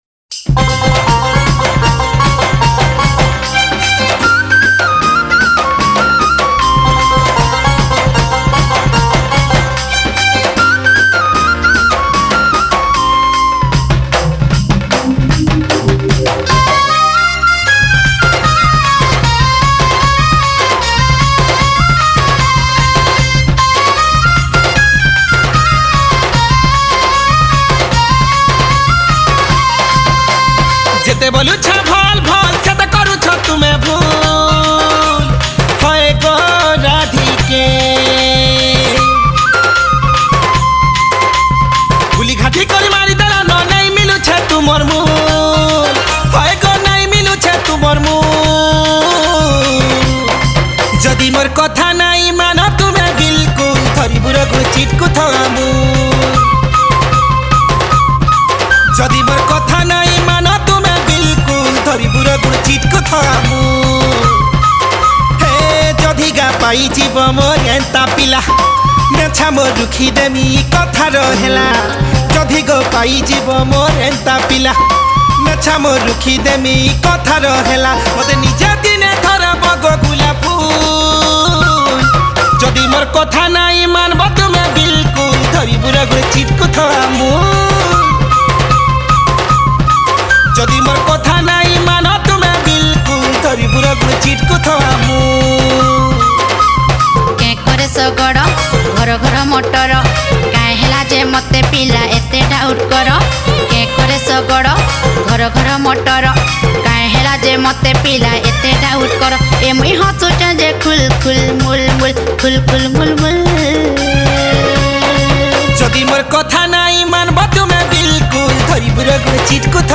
New Sambalpuri Song 2026